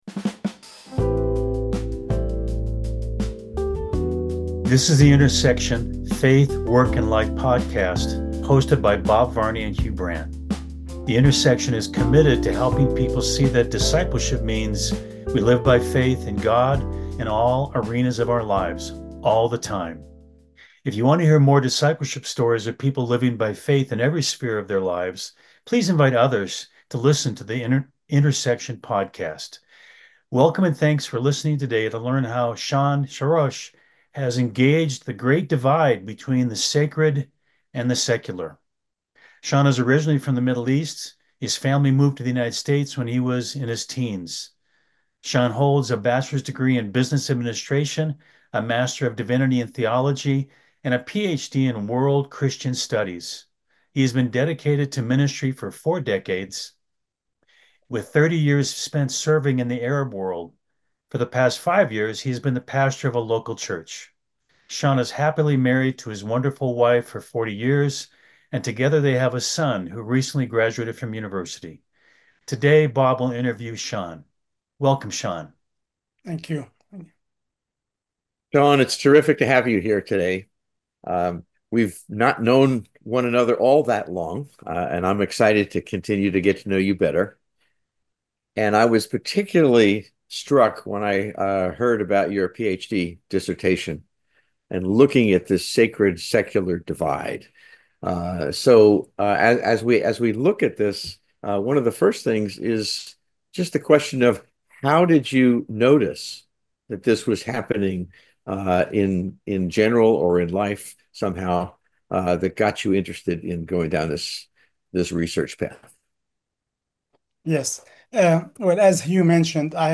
The Intersection Podcast / Bridging the Sacred-Secular Divide - Interview